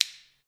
Index of /90_sSampleCDs/Roland L-CD701/PRC_Clap & Snap/PRC_Snaps